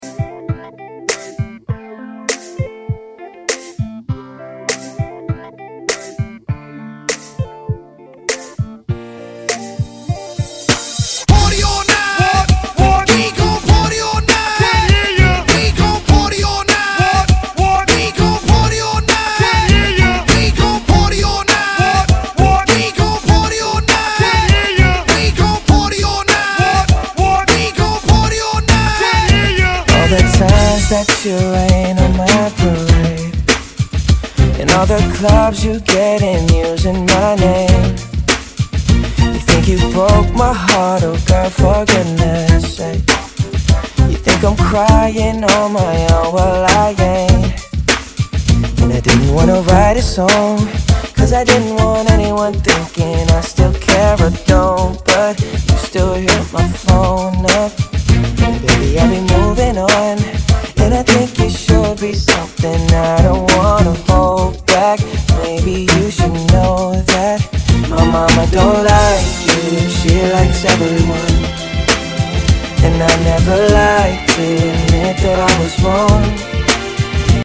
dm Genre: RE-DRUM Version: Clean BPM: 110 Time